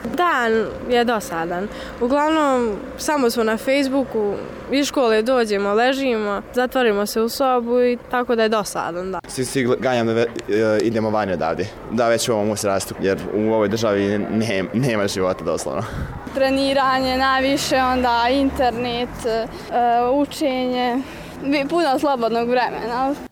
Perspektive u zemlji za mlade nema, tvrde dobojski tinejdžeri:
tinejdžeri